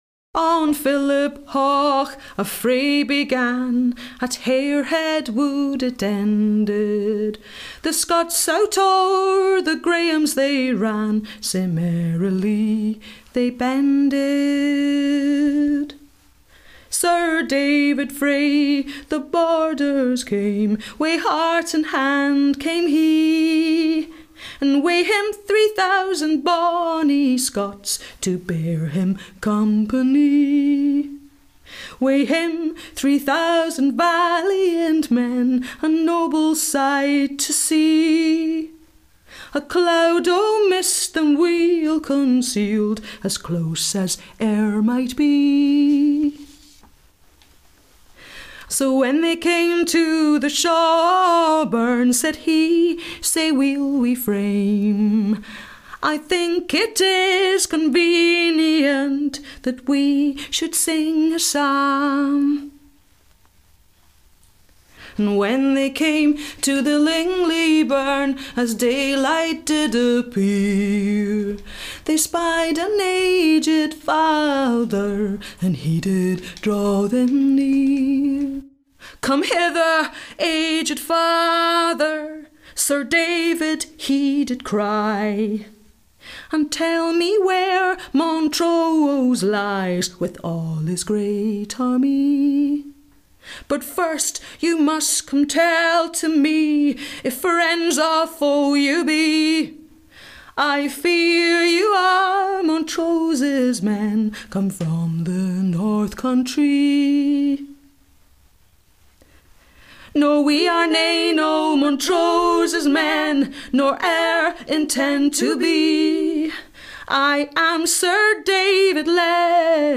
the-ballad-of-philiphaugh-with-reverb.mp3